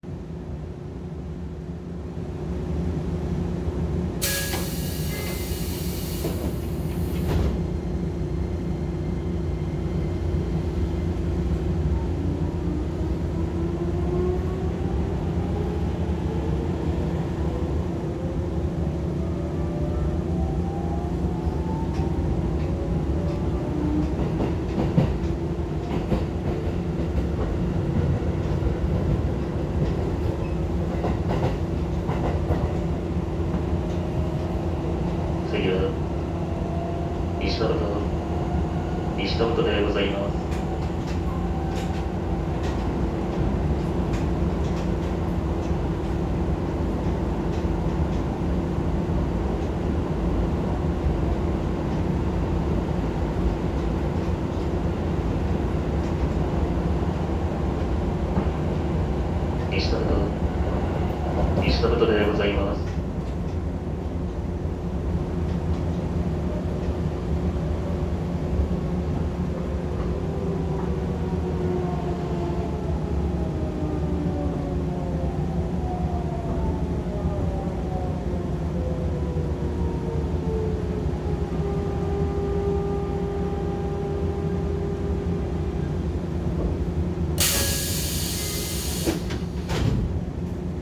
・8800形(ソフト変更後)走行音
【京成千葉線】みどり台→西登戸（1分30秒：2.8MB）
変更前と比べて若干間延びした音になりました。
モーターは三菱製。